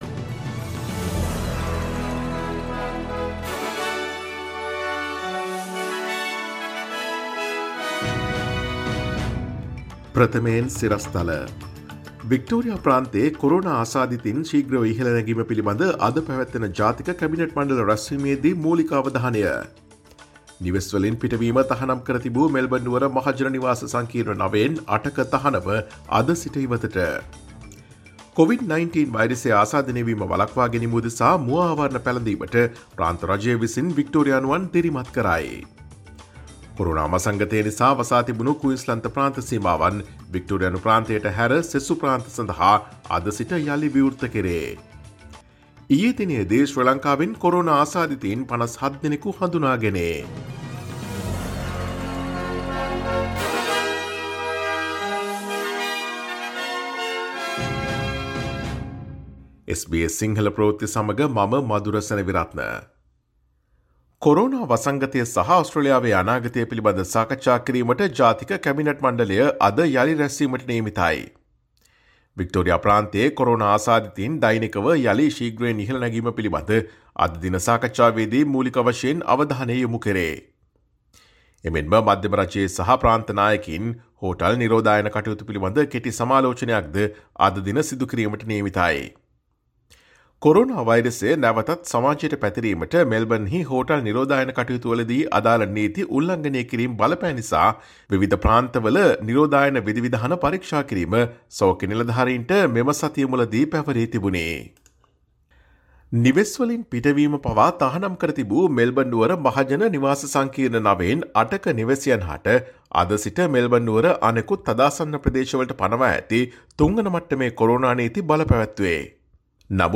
Daily News bulletin of SBS Sinhala Service: Friday 10 July 2020